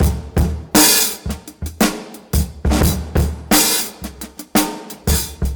• 86 Bpm Breakbeat Sample D Key.wav
Free drum beat - kick tuned to the D note.
86-bpm-breakbeat-sample-d-key-FuQ.wav